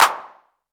normal-hitclap.mp3